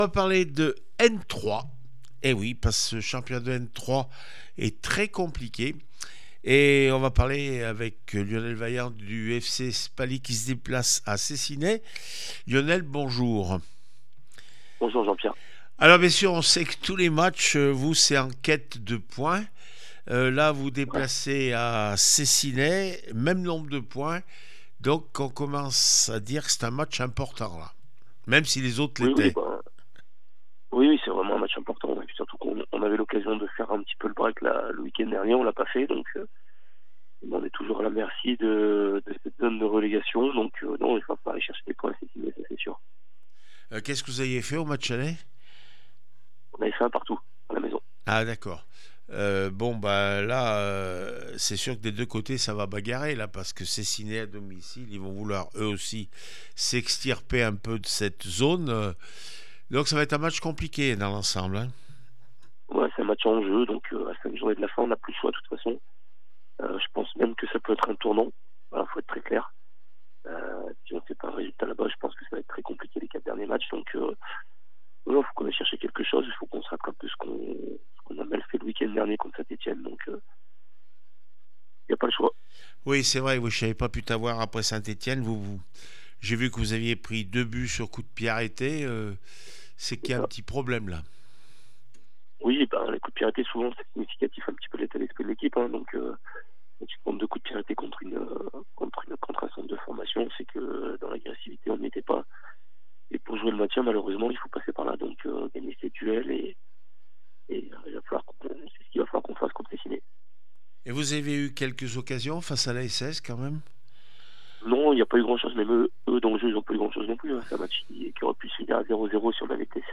12 avril 2025   1 - Sport, 1 - Vos interviews